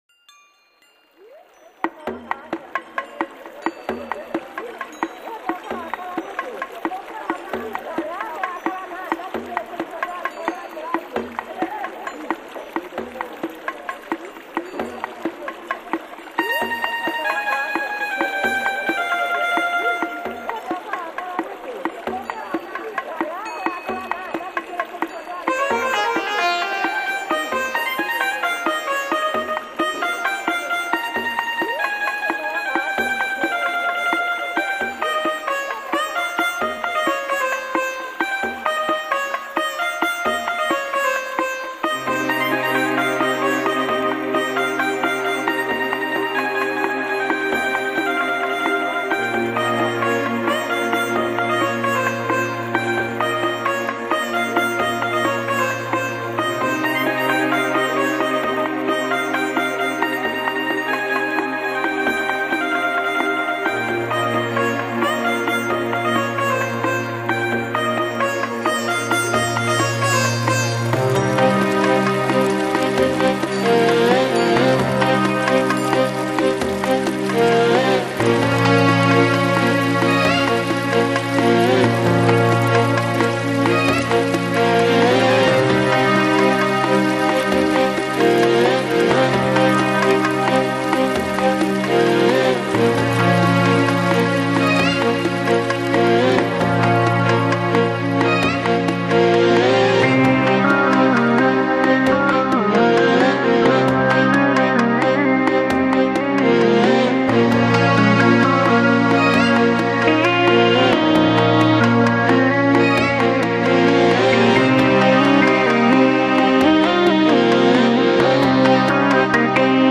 现代电子乐风格的埃及名曲
谱出兼具奇异、神秘、原始、超现代感的音乐图腾。